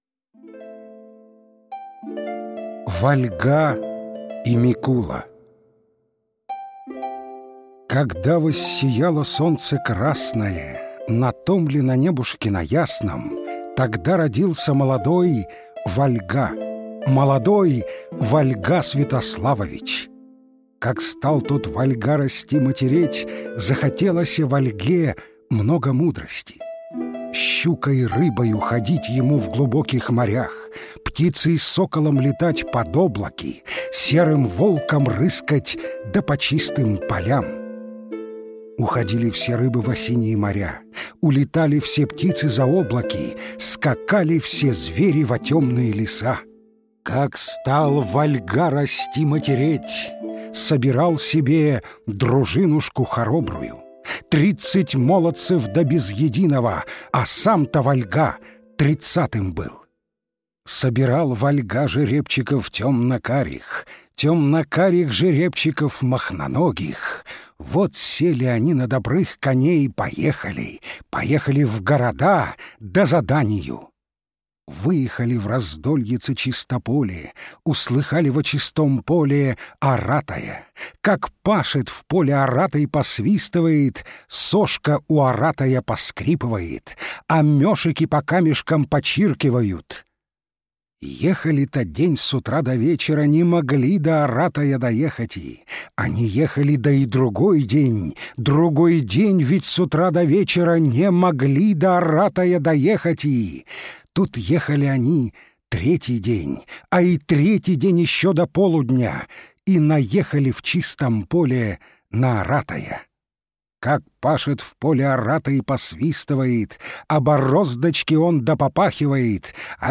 Вольга и Микула - русская народная аудиосказка - слушать онлайн